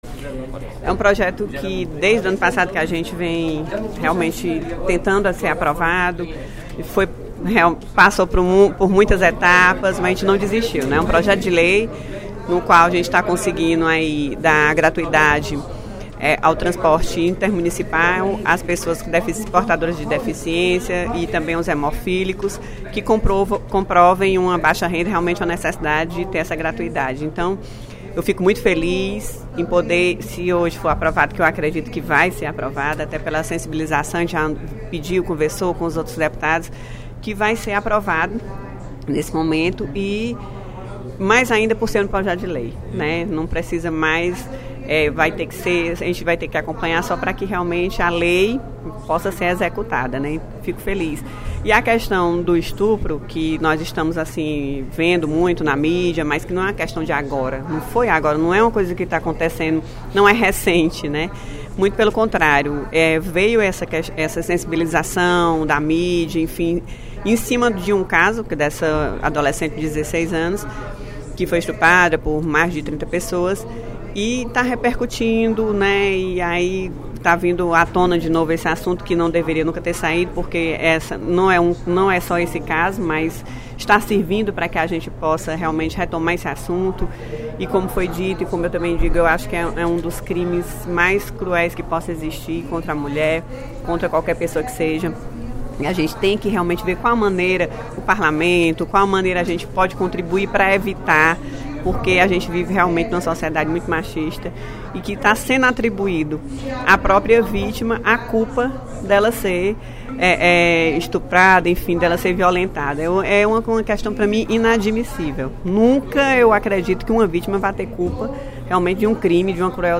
A deputada Augusta Brito (PCdoB) destacou, durante o primeiro expediente da sessão plenária desta quinta-feira (09/06), o projeto de lei  119/15, de sua autoria, juntamente com a deputada Rachel Marques (PT).